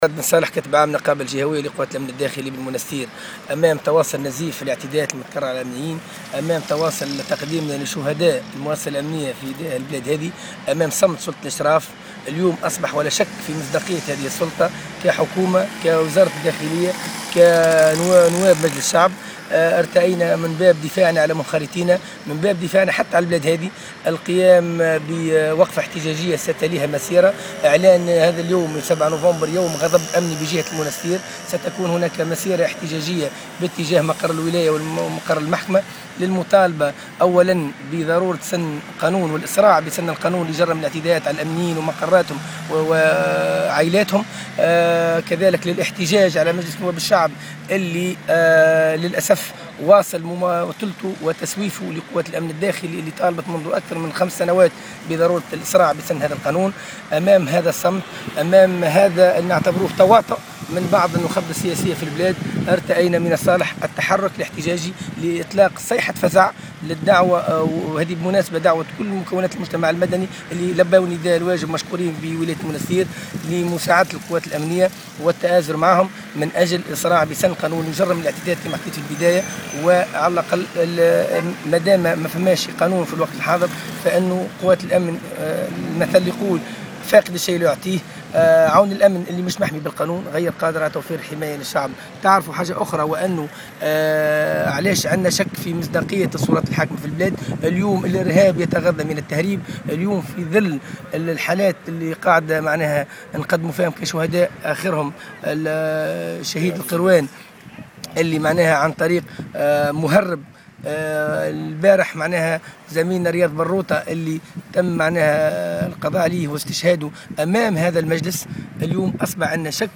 نقابي أمني : عون الأمن غير قادر على توفير حماية للشعب في ظل غياب قانون يحميه